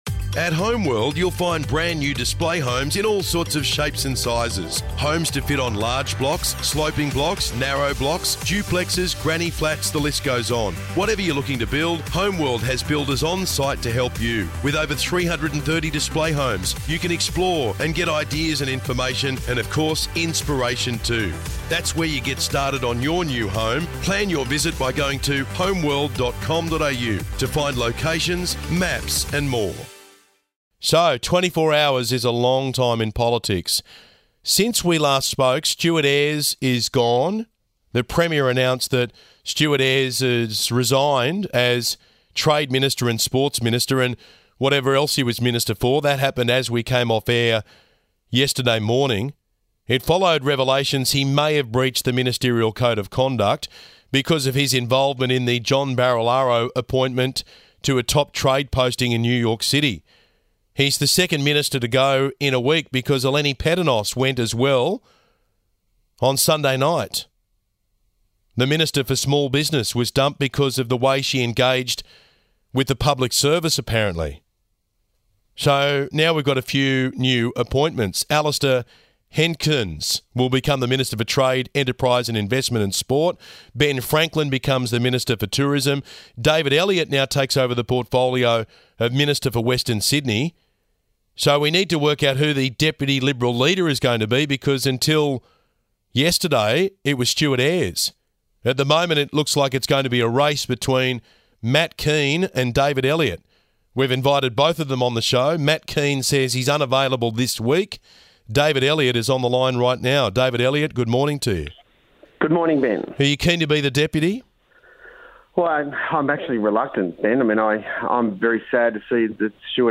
4th August, 2022 Source: 2GB Radio NSW Transport Minister David Elliott has slammed Treasurer Matt Kean over blame-shifting on compensation for taxi plate owners.
Listen below to Transport Minister David Elliott's comments this morning on 2GB Radio: To listen to the interview Click Here